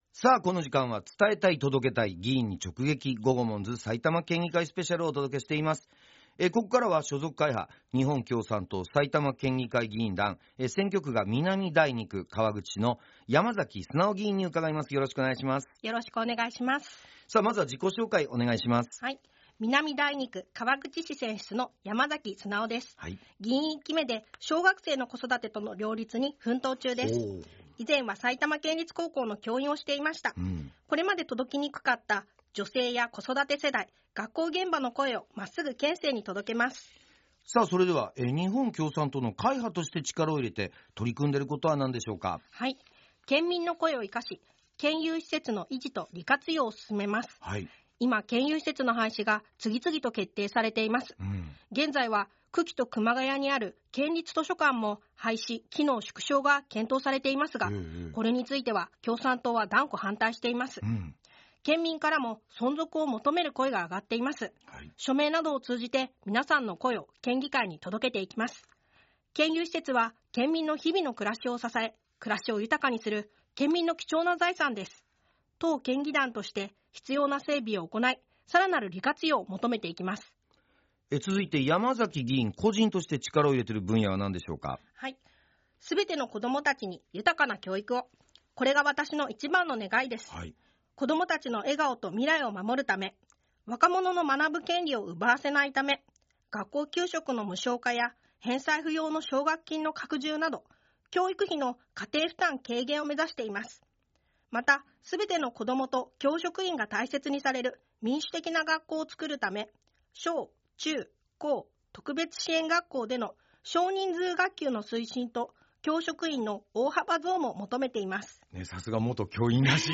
県議会議長や主要会派の議員が「GOGOMONZ」パーソナリティーで落語家の三遊亭鬼丸さんと、所属会派の紹介、力を入れている分野、議員を志したきっかけ、地元の好きなところなどについて軽快なトークを展開しました。
11月10日（月曜日）と11月11日（火曜日）にFM NACK5のスタジオにてラジオ収録が行われました。